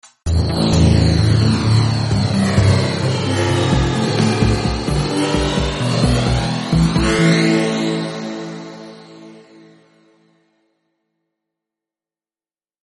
riff-copter_17153.mp3